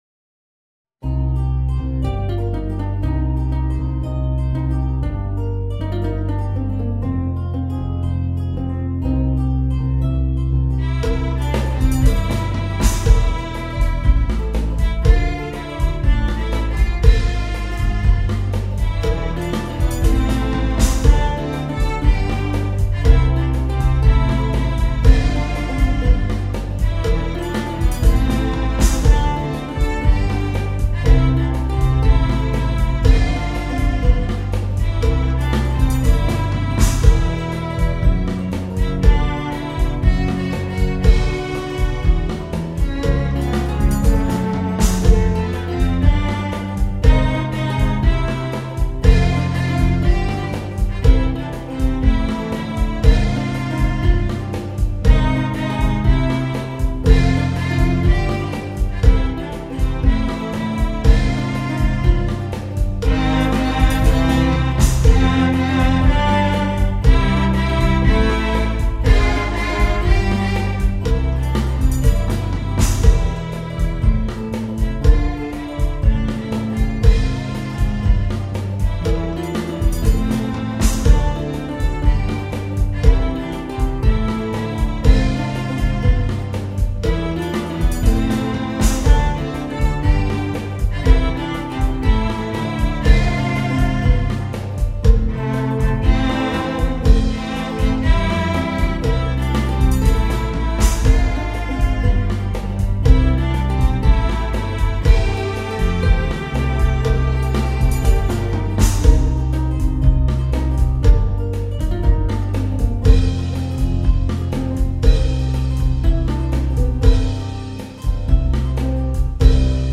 Arabískur eyðimerkurrokkari fyrir The Icelandic Pop Orchestra